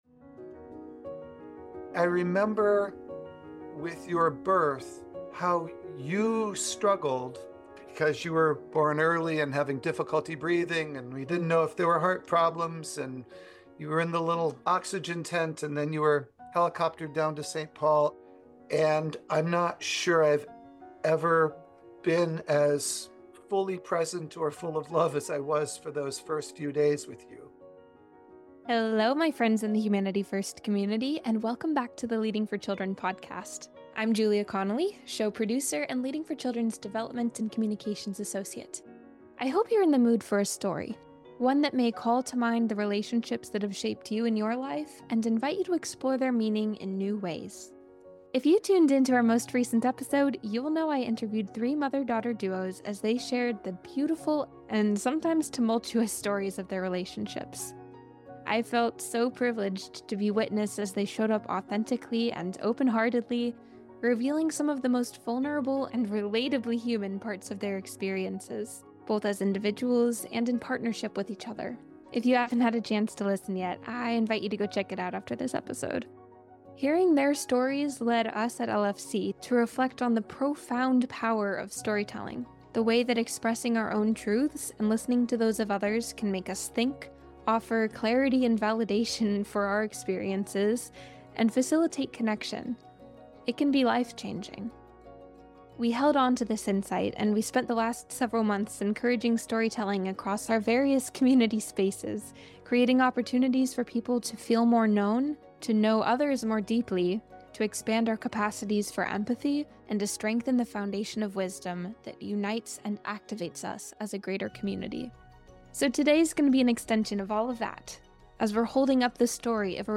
In Season 2 Episode 5 of The Leading for Children Podcast, parents and children share how storytelling builds connection, understanding, and shared meaning across generations.